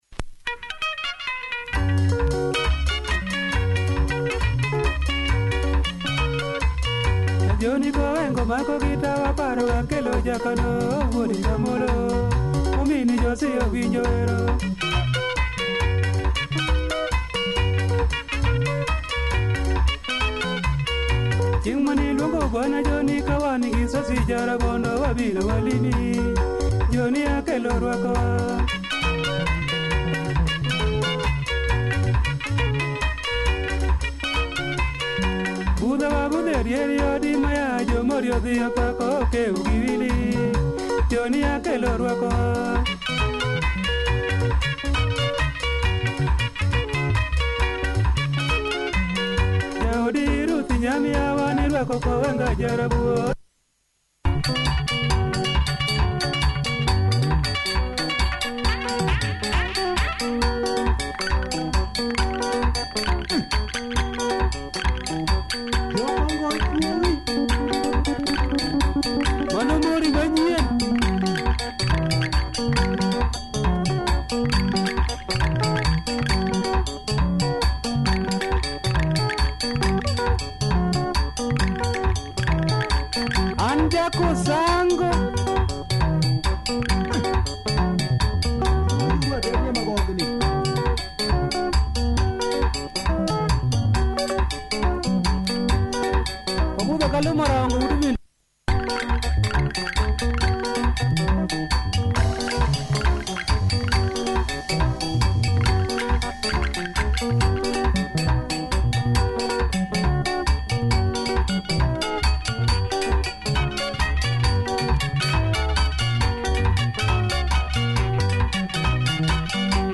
Nice luo benga, clean copy! https